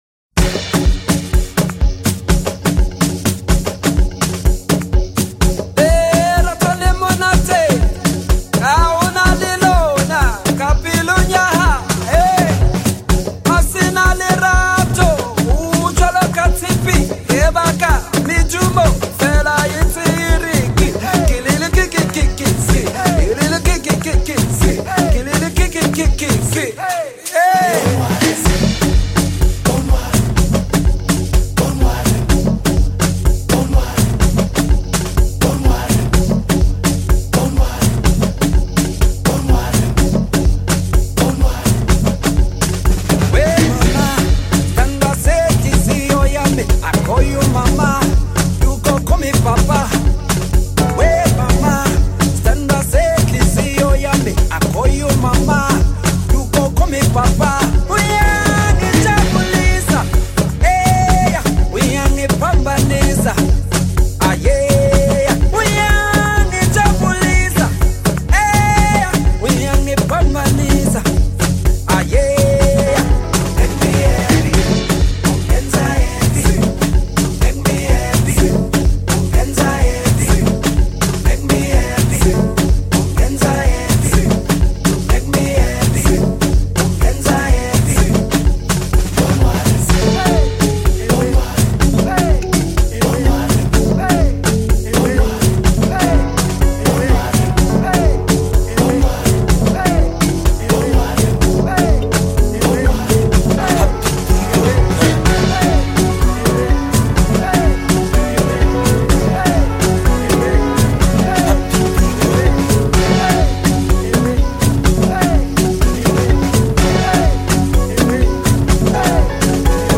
hefty Pop-Kwaito mixture